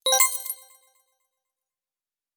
Coins (24).wav